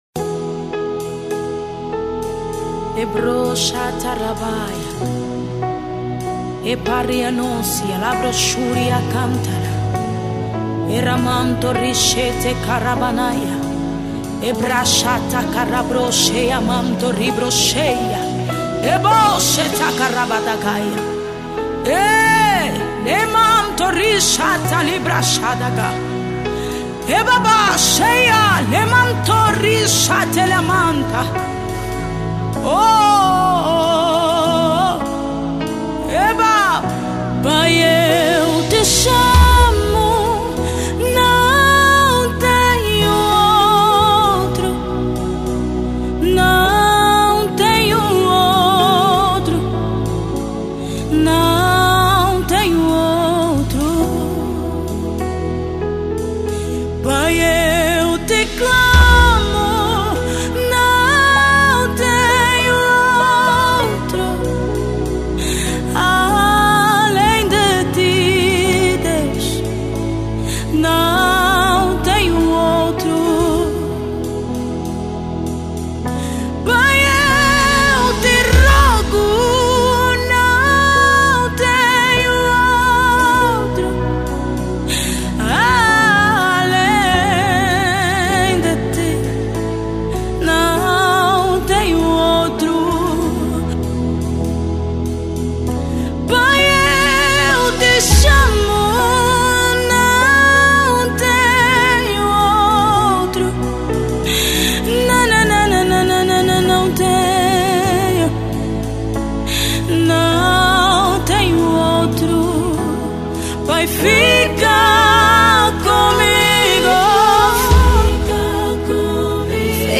Gospel 2025